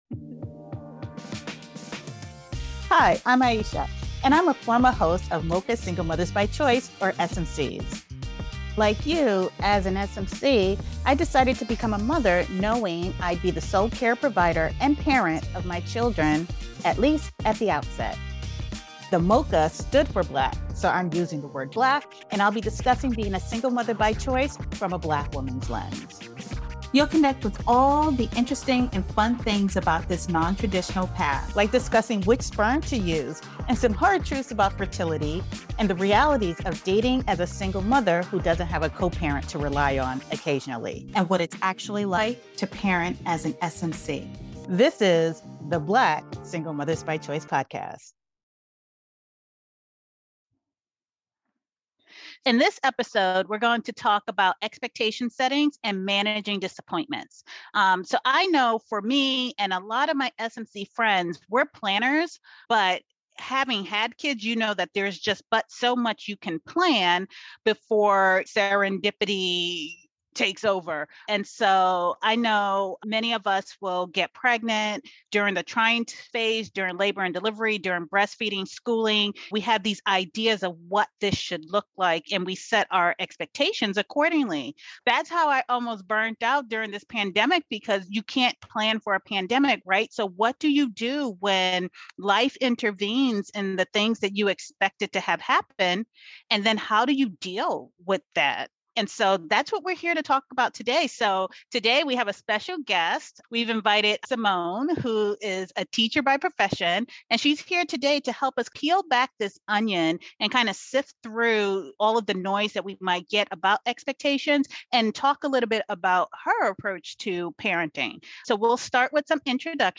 They discuss the importance of realistic developmental expectations for children, especially during the pandemic, and offer insights into breastfeeding, weaning, and the pressures of societal expectations. Don't miss this candid conversation, and remember to subscribe to the Black Single Mothers by Choice and Start to Finish Motherhood YouTube channels and Instagram pages.